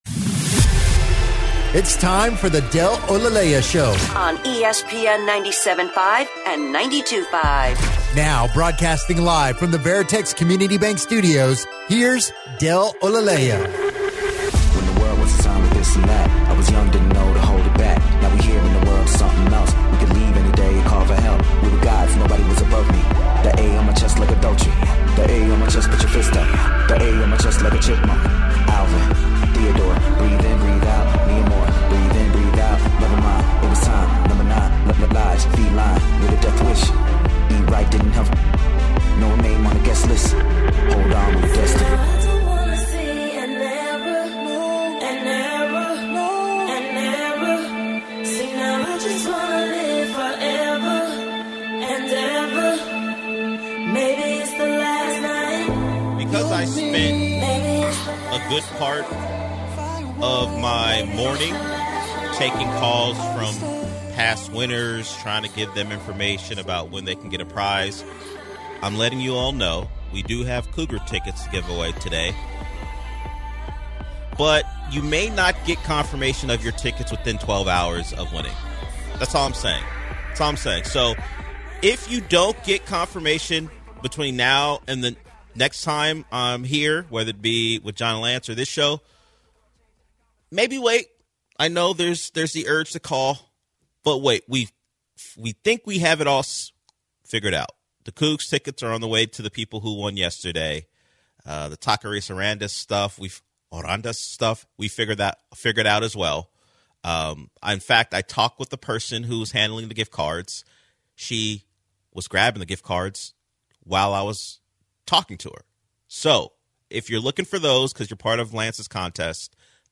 Caller asks about Texans/Ravens and Russo Brothers new comic book film